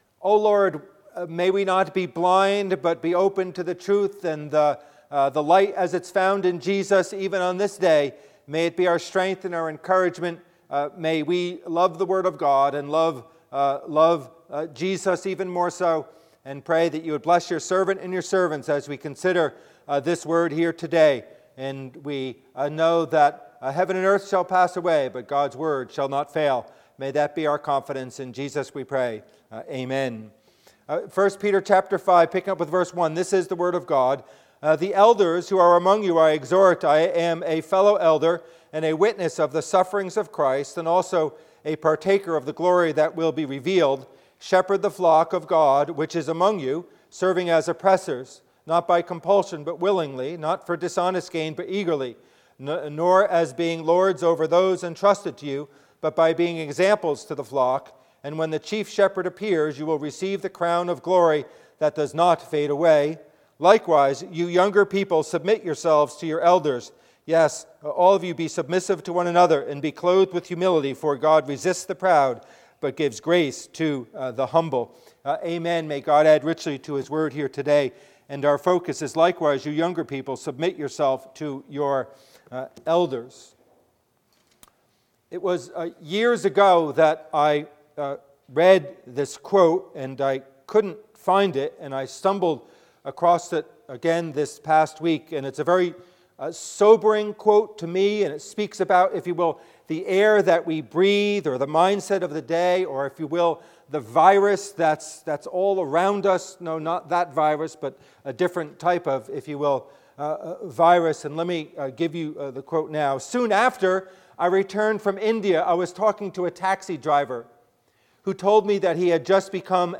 Passage: 1 Peter 5:1-5 Service Type: Worship Service « Sermon